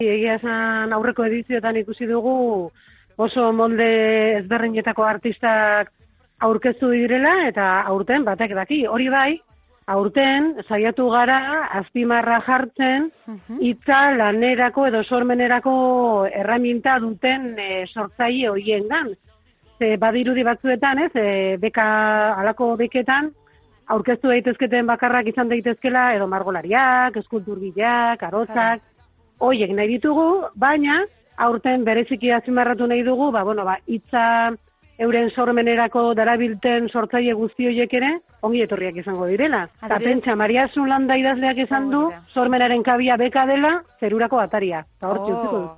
Baina elkarrizketan aurten egindako azkenego apostu berriari buruz hitz egin dugu: egonaldiak.